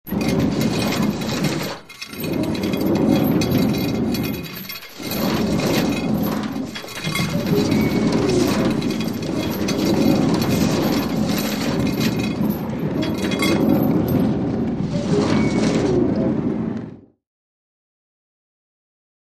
Chains Dragging | Sneak On The Lot
Chain Rattle; Metal And Wood Object Rolling Along With Chain Movement.